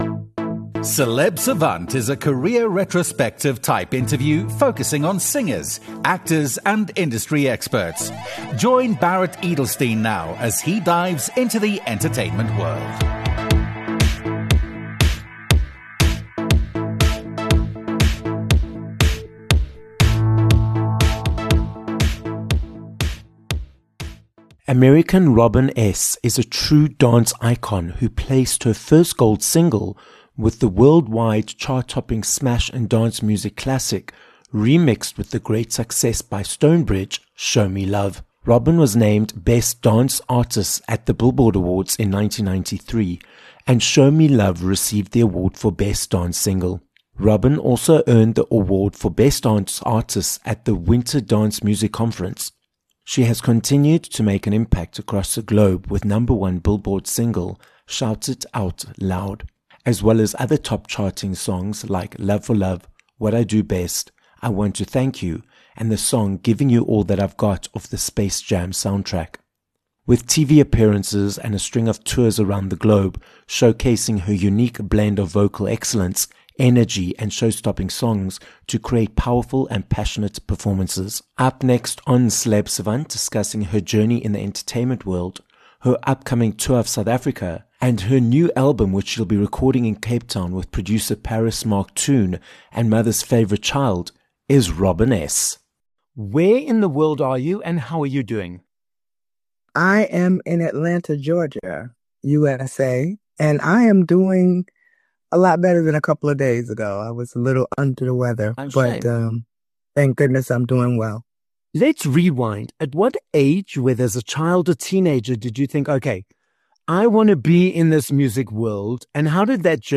Robin S - an American singer, artist, and dance icon - joins us on this episode of Celeb Savant. Robin tells us about her first professional gig performing with a band… which helped her hone her craft as an entertainer, and the reason behind her stage name.